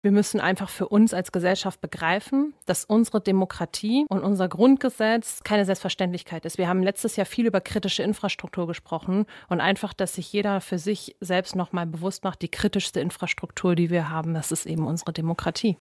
Sommerinterview mit Laura Kraft
Unsere Bundestagsabgeordnete war zu Gast bei Radio Siegen.